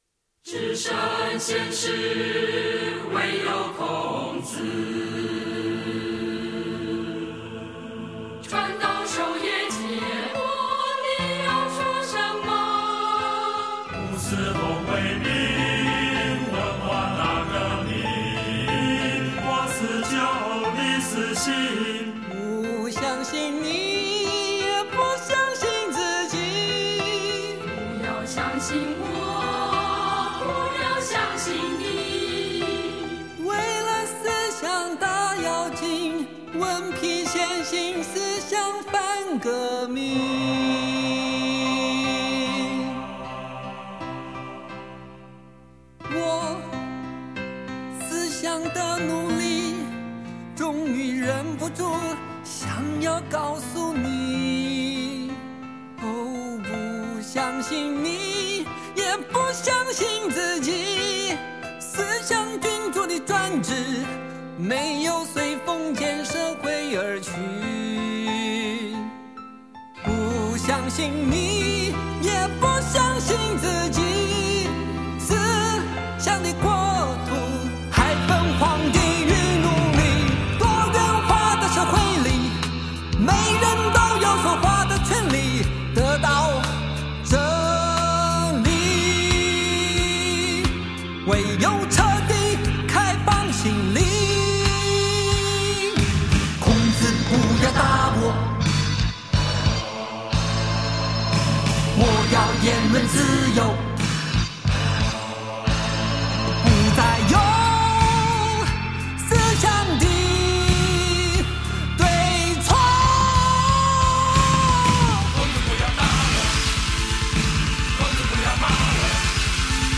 会选择用最艰难的乐曲形式，来表达心中的理想，其实动机是很大单纯的。